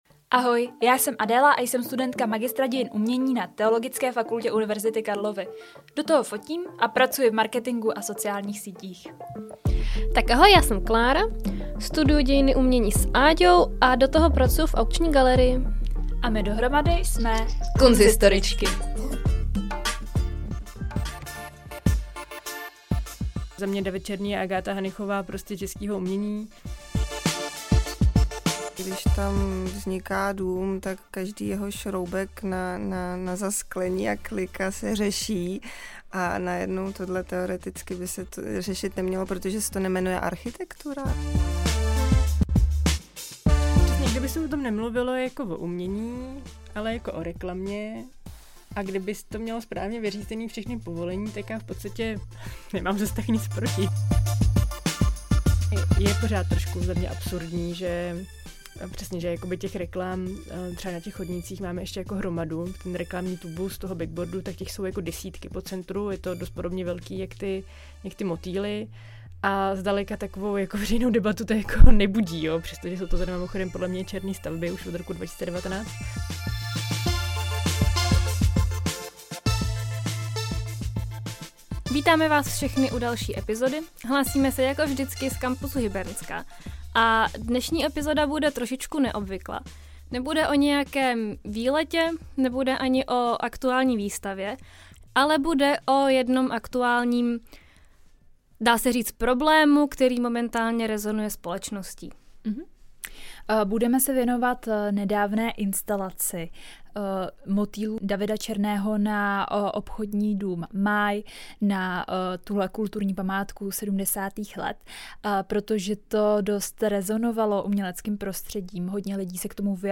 Společností nedávno silně rezonovala instalace motýlů od Davida Černého na kulturní památku 70. let v Praze na Národní třídě, Obchodní dům Máj. K diskusi nad motýly, a tím, jaká se s nimi pojí problematika, jsme si do studia přizvaly kunsthistoričku a zastupitelku hlavního měs...